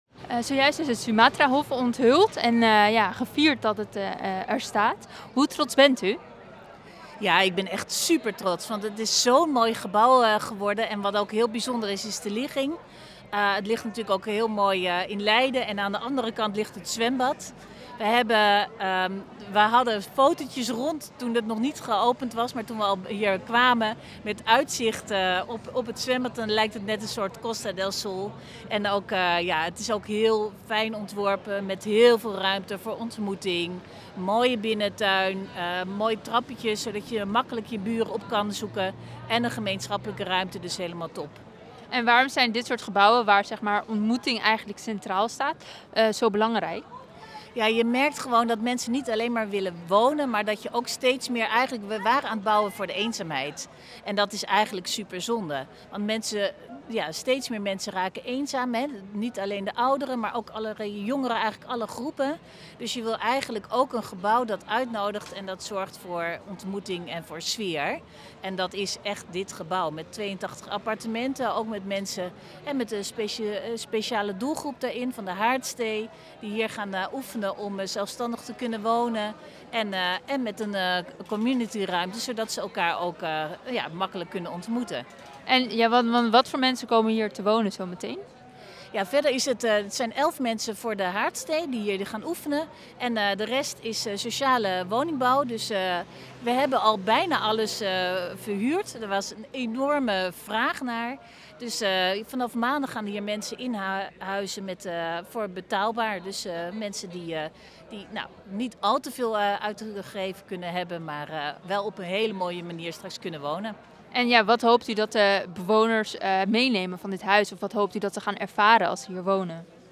AUDIO: Verslaggever